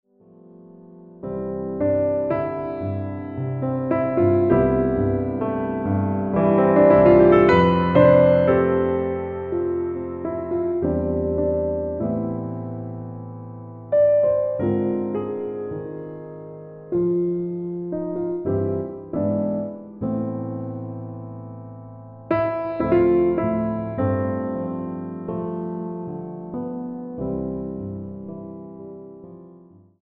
including original jazz, new age and meditation music.